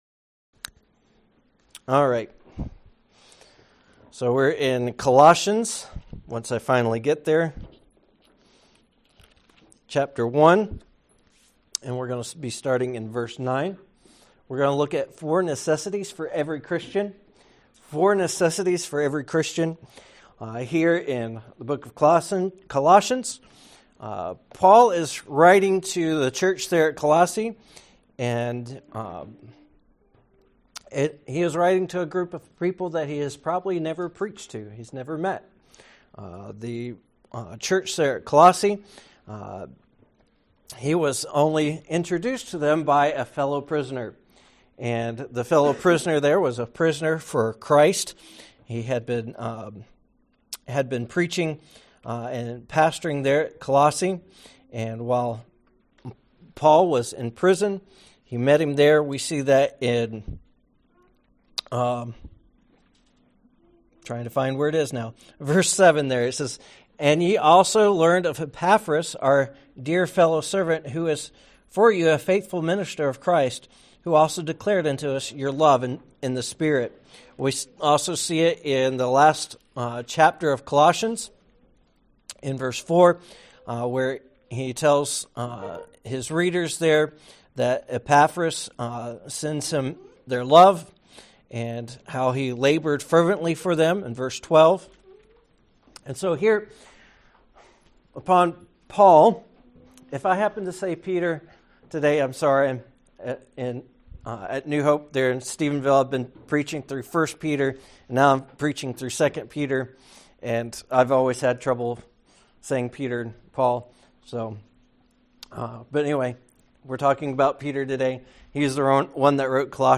Sermons
Sermons: Four Necessities for Christians Home Sermons Guest Preacher Four Necessities for Christians